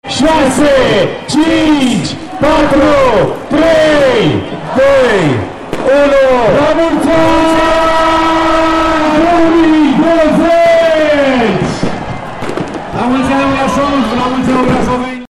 Până înainte de miezul nopții, inima orașului era plină atât de adulți, cât și copii și seniori, cu toții bucurându-se de concerte.
Cu câteva minute înainte de miezul nopții, pe scenă a urcat primarul municipiului Brașov, George Scripcaru, care a început numărătoarea inversă până la trecerea în 2020: